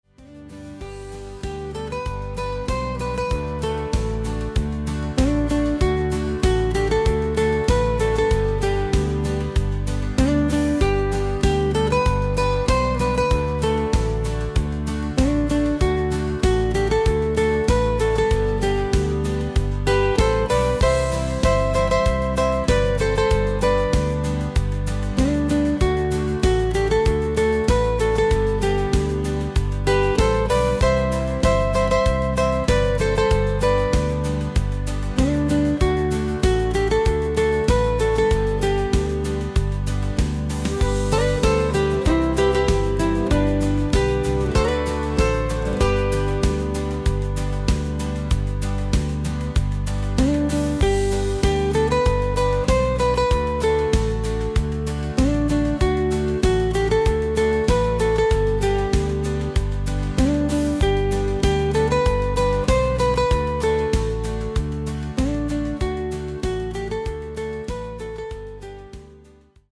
Rhytmisches Wanderlied